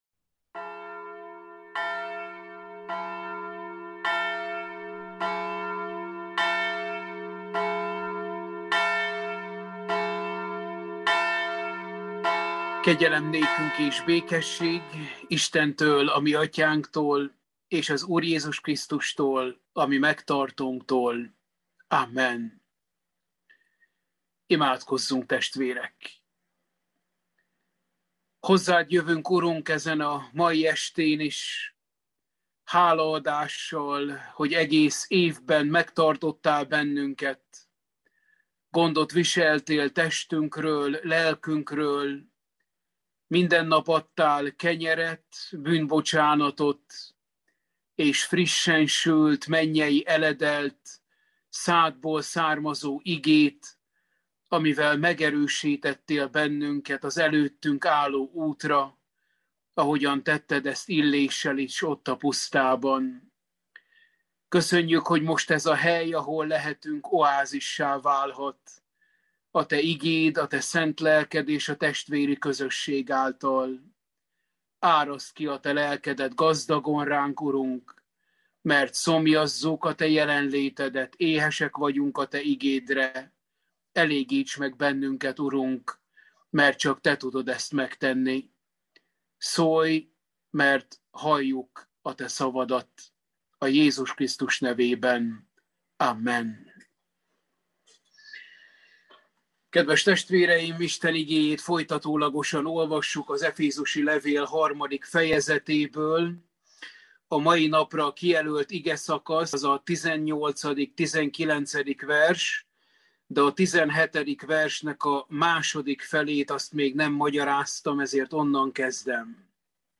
Efézusi levél – Bibliaóra 11